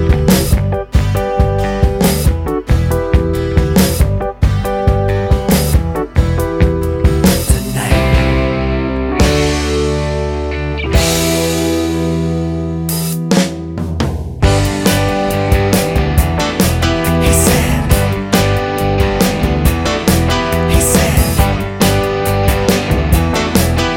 No Saxophone Pop (1970s) 4:47 Buy £1.50